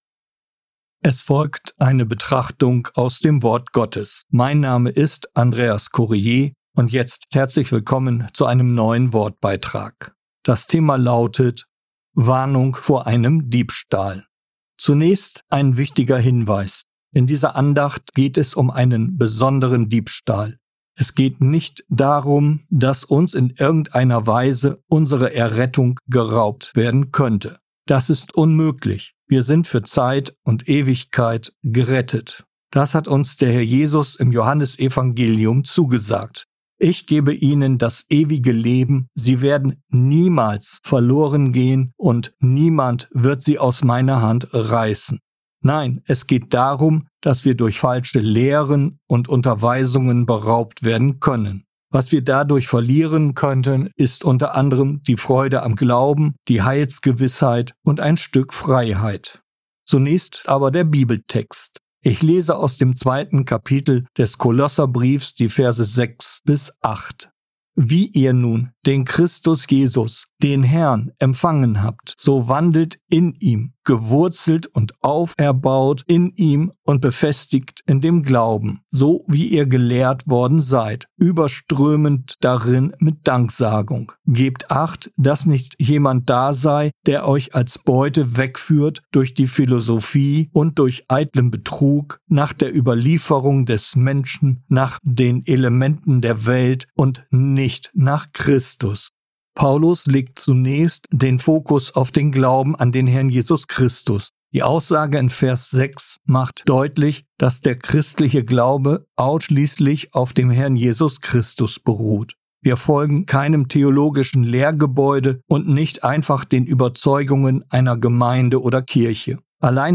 In dieser Andacht geht es um einen besonderen Diebstahl.